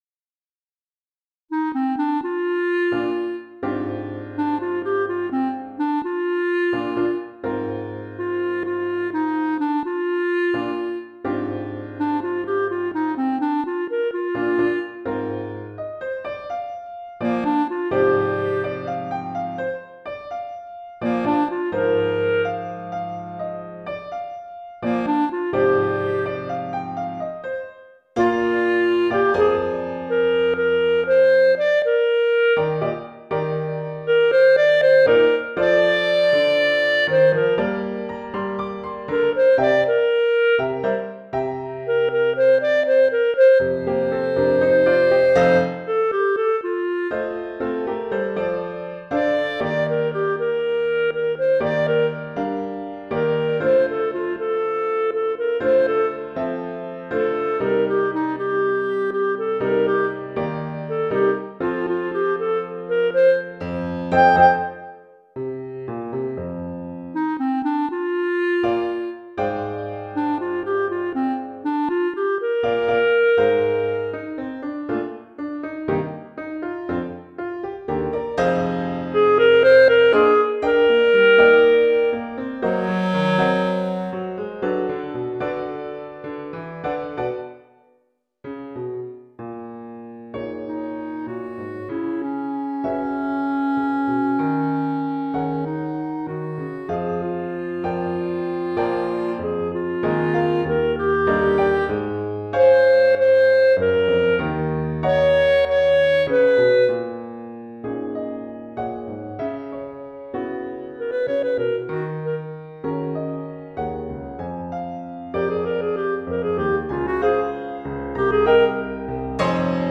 for Clarinet & Piano